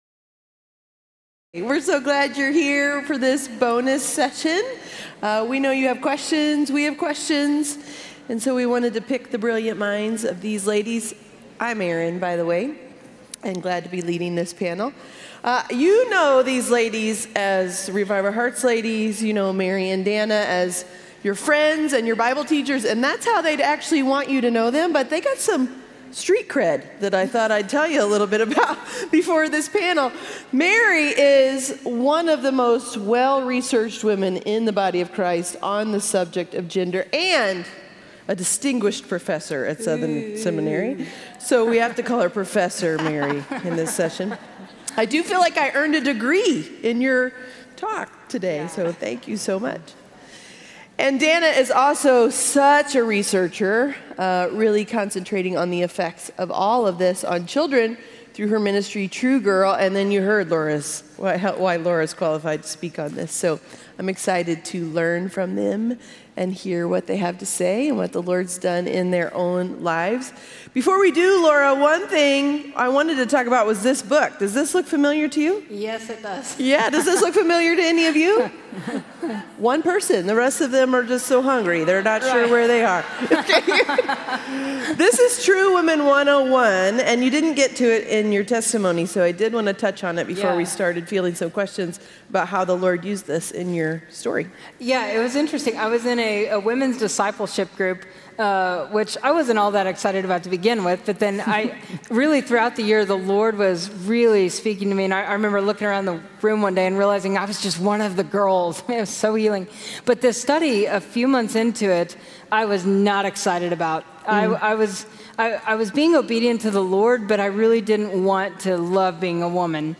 Grounded Panel Discussion